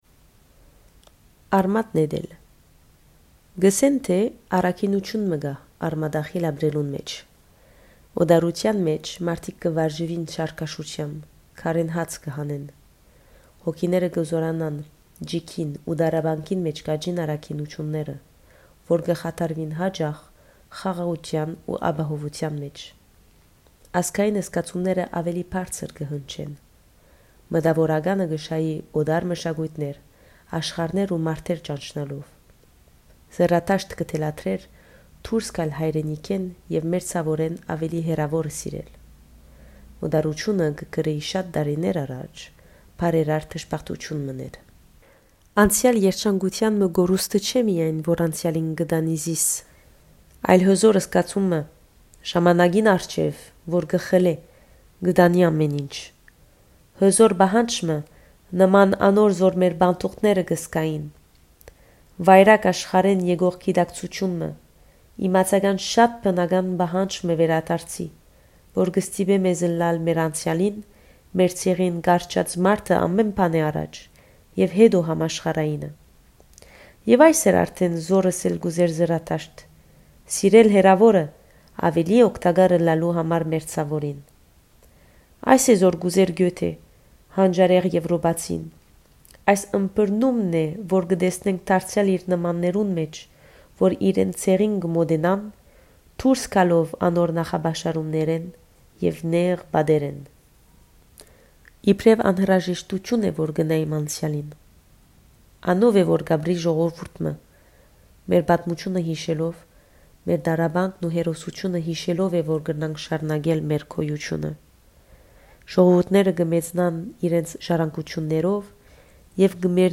Կ’ընթերցէ
Երաժշտութիւն. Allégro (Emmit Fenn),  Ludwig Van Beethoven